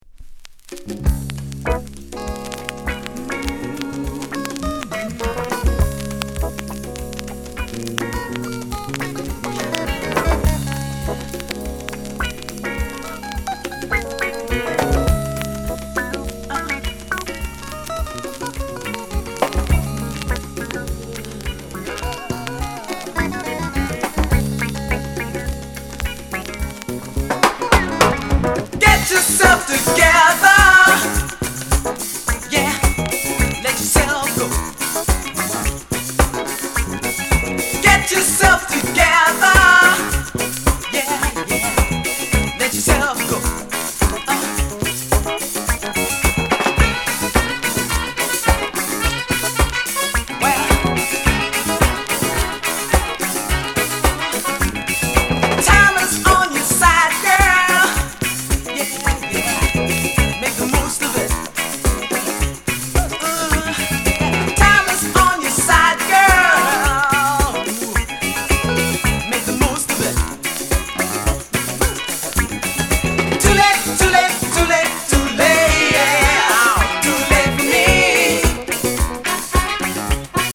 Genre:  Soul/Reggae